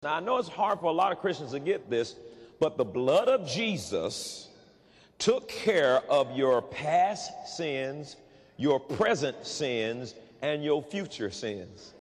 (To listen to Creflo Dollar speak those deadly words, click